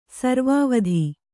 ♪ sarvāvadhi